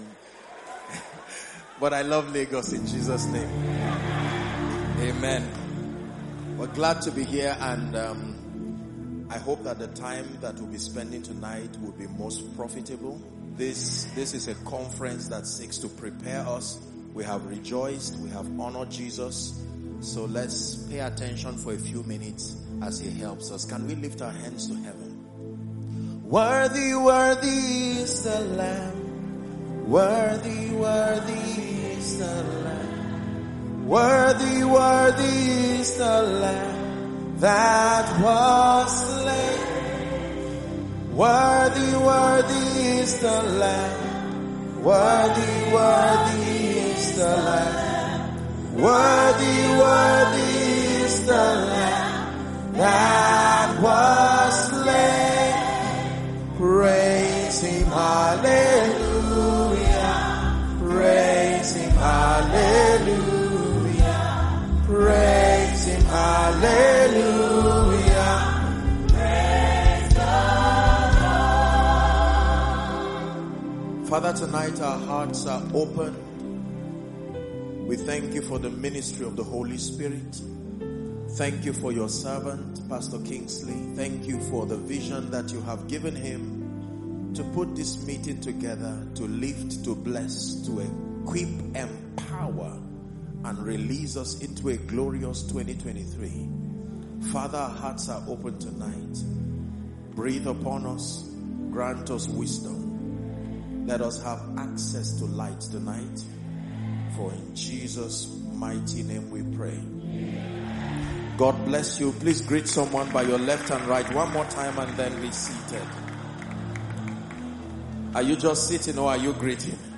In this powerful message at the Gaining Momentum Conference 2023